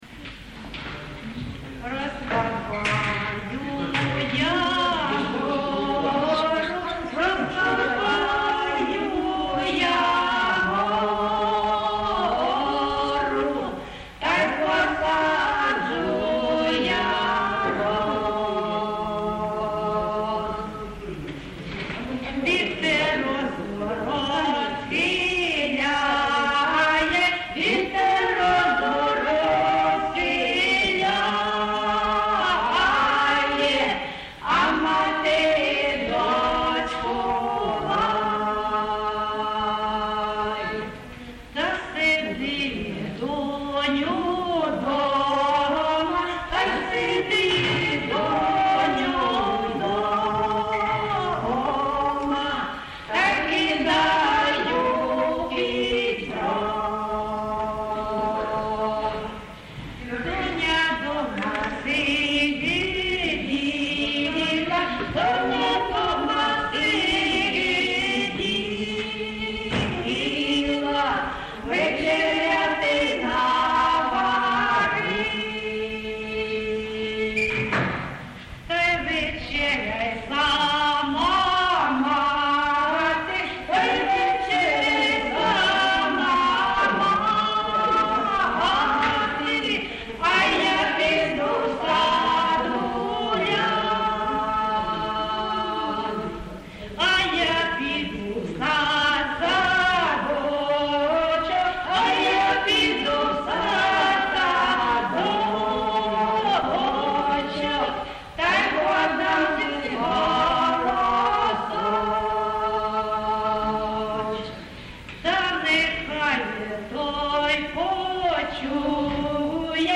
ЖанрПісні з особистого та родинного життя
Місце записус-ще Зоря, Краматорський район, Донецька обл., Україна, Слобожанщина
(співають також невідомі виконавиці)